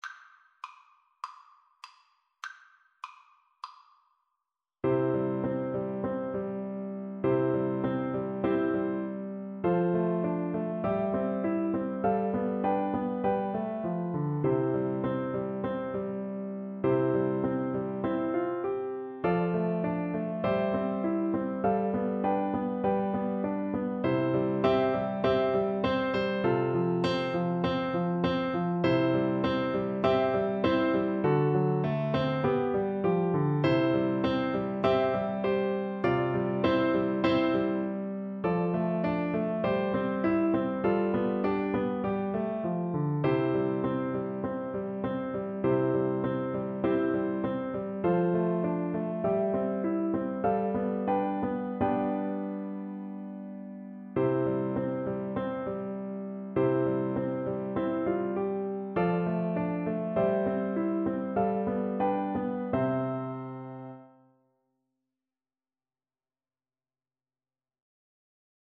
Free Sheet music for Tin Whistle (Penny Whistle)
4/4 (View more 4/4 Music)
Tin Whistle  (View more Easy Tin Whistle Music)
Traditional (View more Traditional Tin Whistle Music)